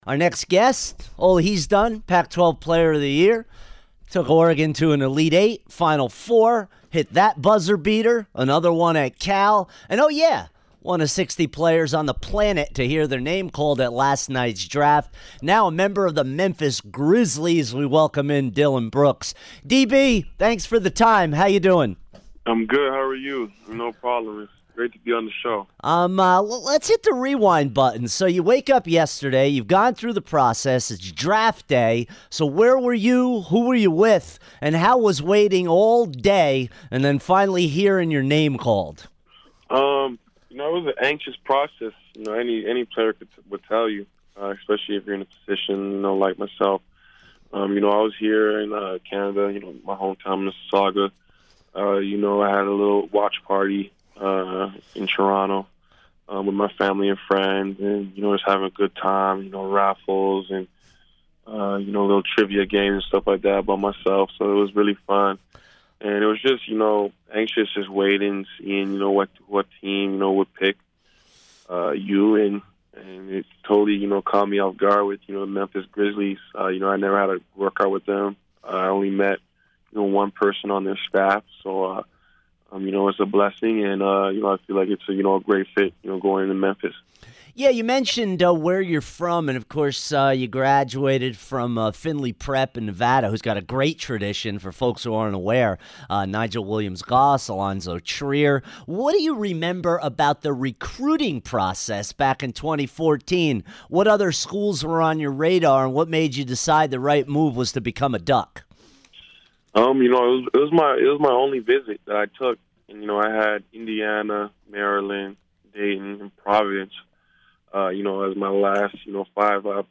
Dillon Brooks Interview 6-23-17